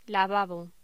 Locución: Lavabo
voz